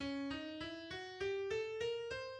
Two diminished seventh chords in the octatonic scale (one red, one blue) may be rearranged as the alpha chord